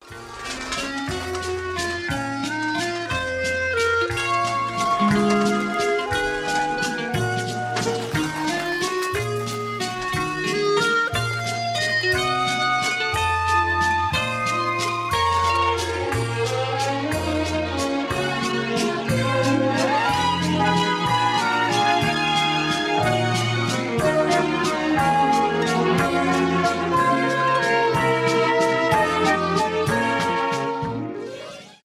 без слов
инструментальные